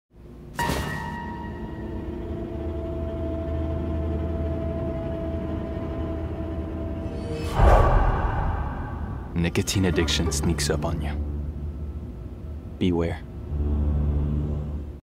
Commercial (RSEQ) - EN